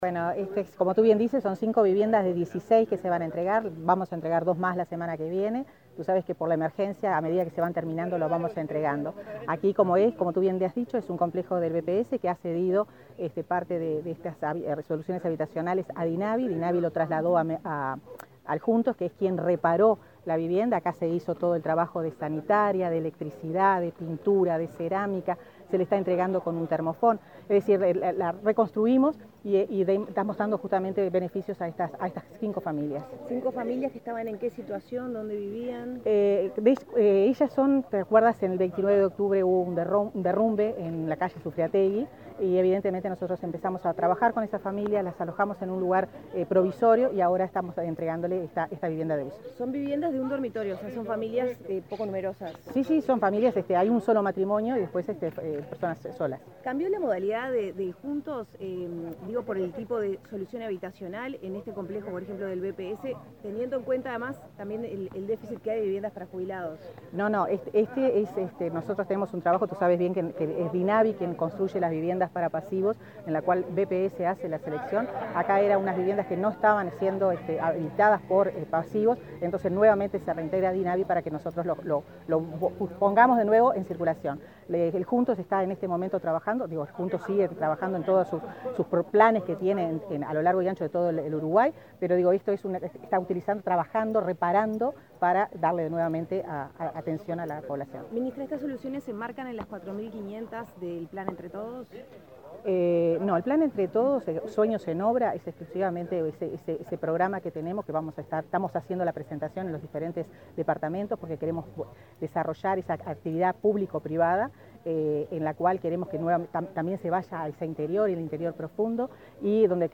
Declaraciones a la prensa de la ministra de Vivienda, Irene Moreira
Declaraciones a la prensa de la ministra de Vivienda, Irene Moreira 16/06/2022 Compartir Facebook X Copiar enlace WhatsApp LinkedIn Este miércoles 16, la ministra Irene Moreira entregó viviendas del complejo habitacional 87, ubicado en el barrio Tres Ombúes, de Montevideo, y luego dialogó con la prensa.